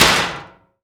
metal_impact_light_thud_02.wav